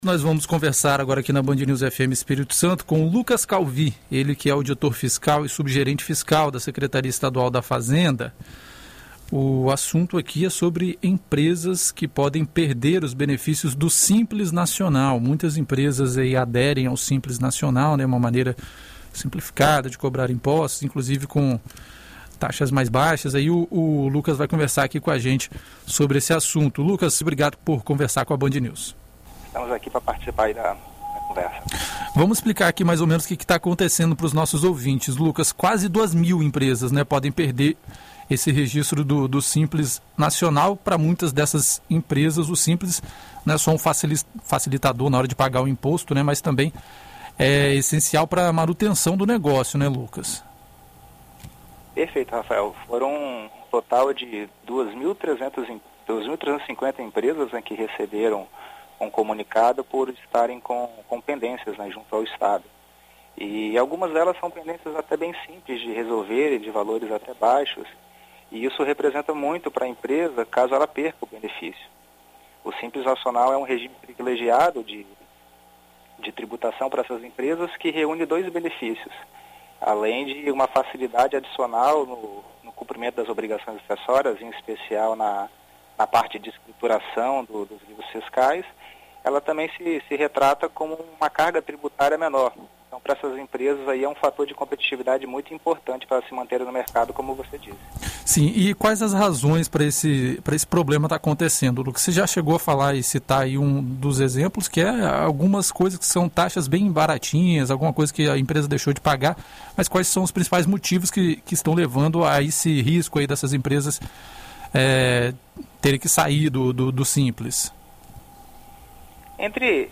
Em entrevista à BandNews FM Espírito Santo nesta segunda-feira (25)